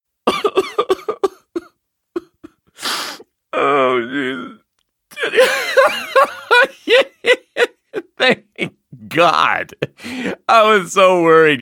crying-laughter_01